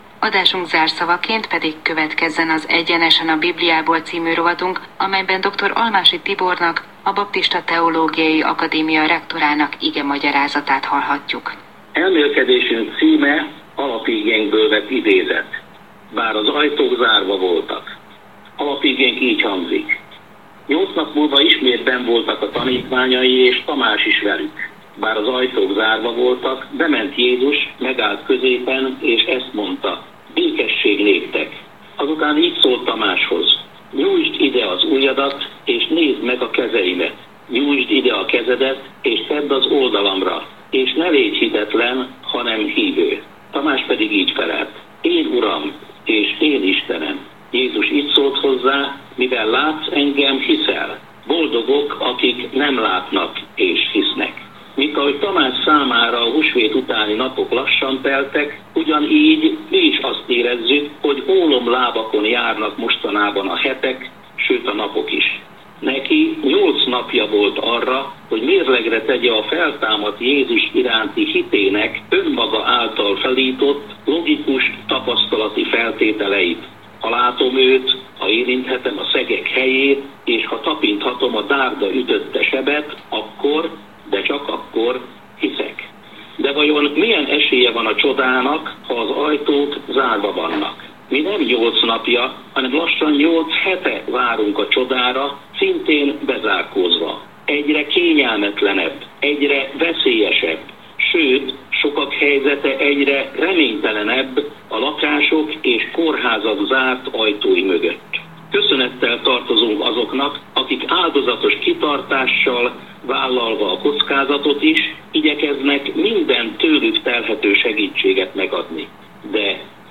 Áhítat a Kossuth rádióban